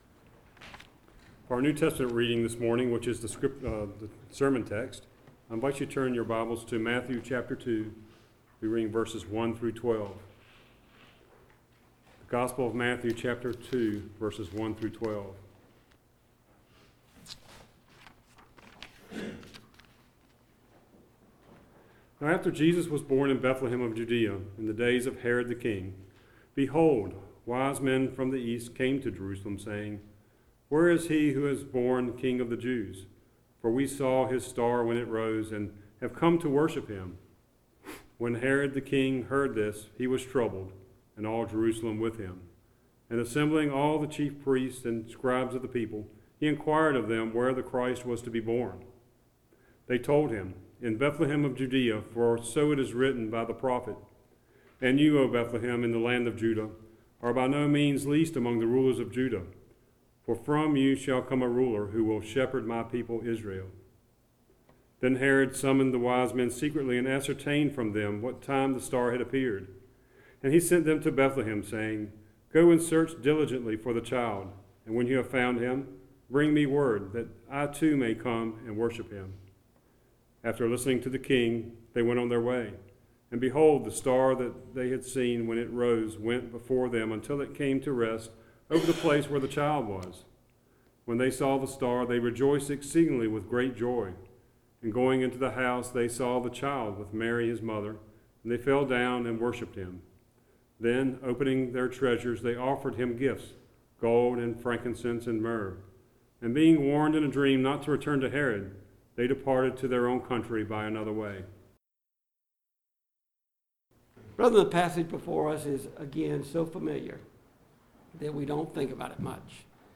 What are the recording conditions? Passage: Matthew 2:1-12 Service Type: Sunday Morning « Immanuel Is Come Resolved to Seek the Lord